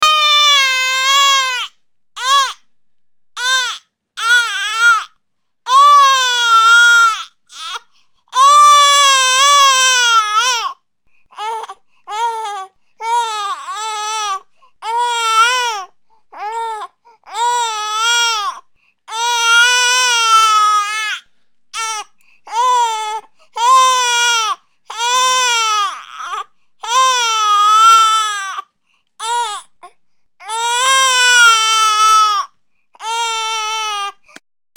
Baby Crying Sound Effect (320 kbps)